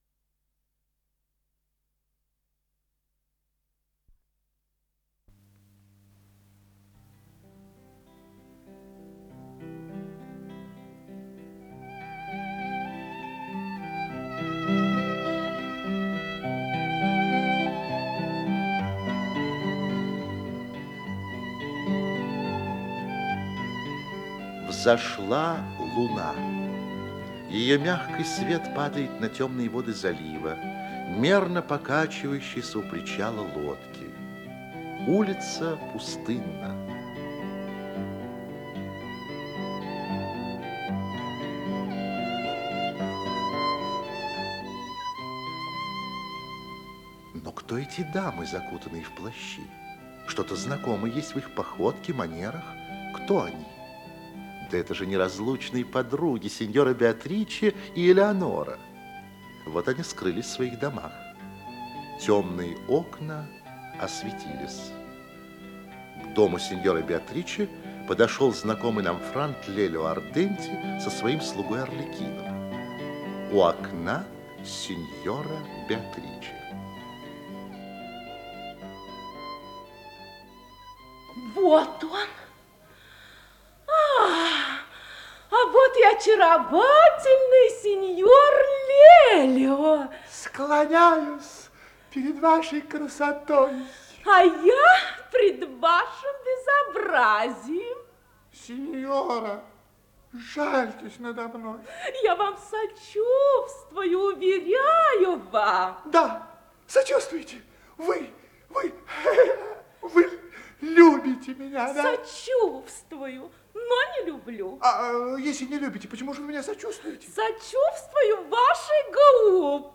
Исполнитель: Артисты московских театров
Радиоспектакль